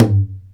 MADAL 1A.WAV